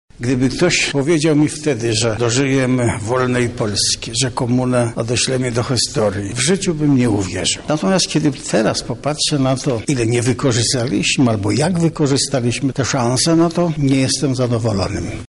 Lech Wałęsa gościł dziś na Katolickim Uniwersytecie Lubelskim.
Wałęsa przyznaje, że dzięki przemianom ustrojowym stał się najszczęśliwszym człowiekiem w galaktyce: